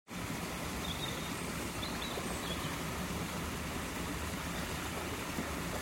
Escuchas el sonido del río mientras caminas.
Rio.mp3